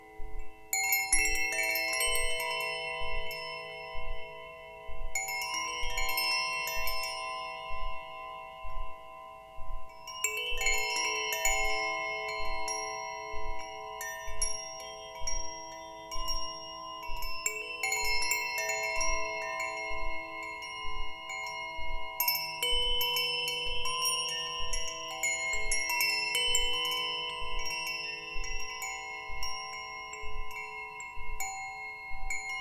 Gartenklangspiel Mira - der Klang des wundersamen Sterns - silberhell und leicht
Unser Gartenklangspiel Mira kann leicht kreisend bewegt oder sanft gependelt werden. Im Freien bringt der Wind Mira zum Klingen.
Jedes Klangspiel wird sorgfältig gestimmt, bis sich die Töne zu einem harmonischen Ganzen verweben.
Stimmung: E G B C E A B C - 432 Hz
MaterialAluminium